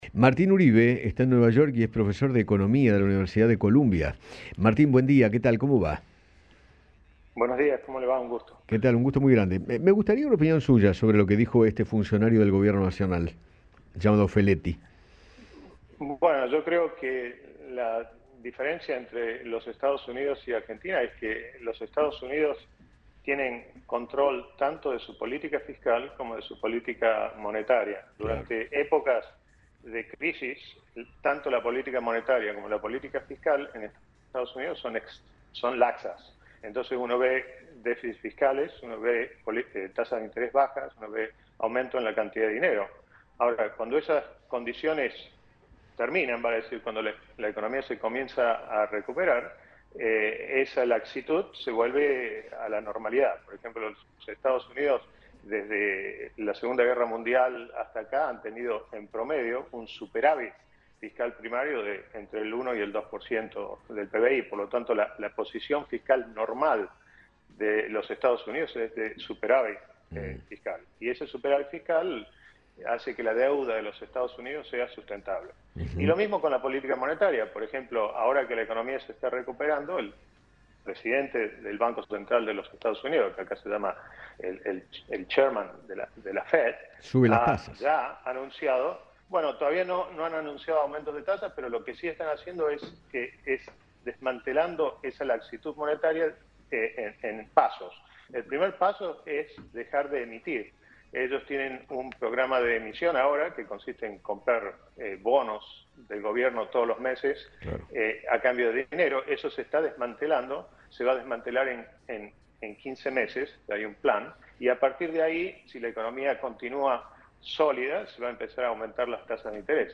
conversó con Eduardo Feinmann sobre los dichos del secretario de Comercio Interior